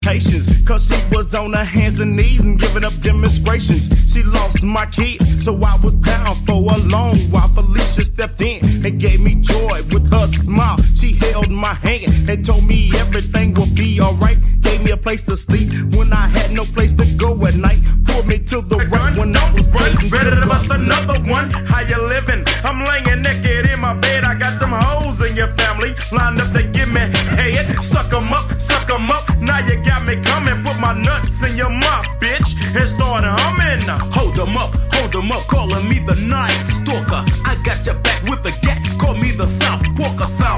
southern gangsta rap